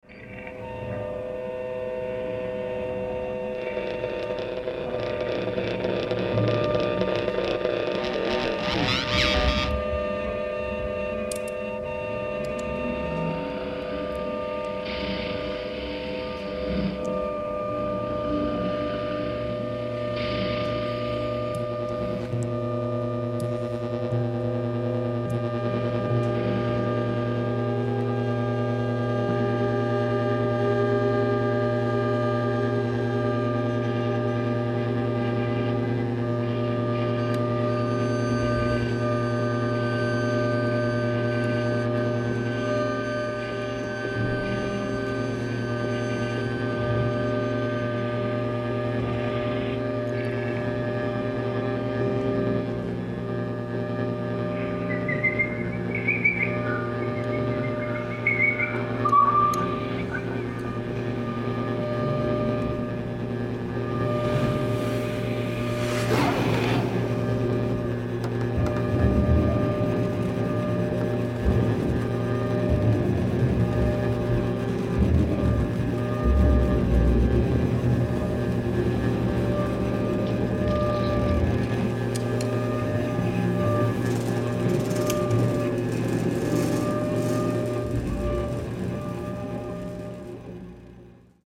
double bass
bass clarinet, saxophones, electronics
cello
guitar, lap steel, electronics
drums